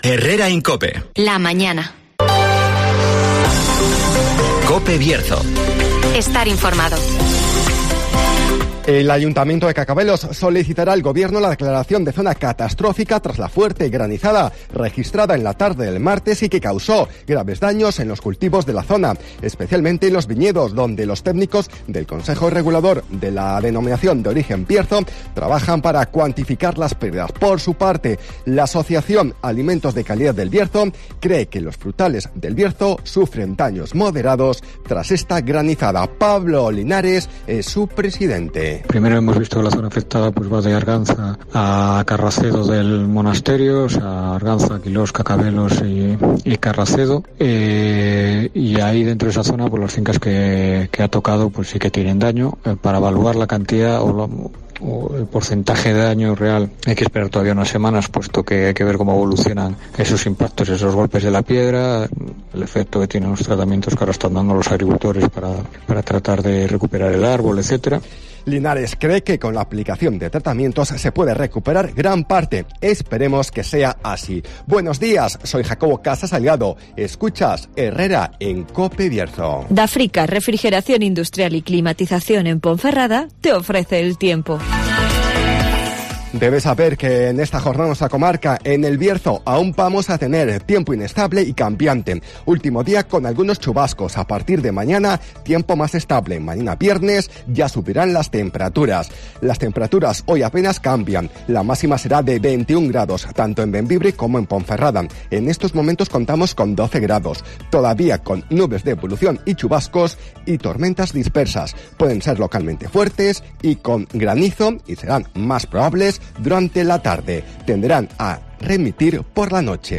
INFORMATIVOS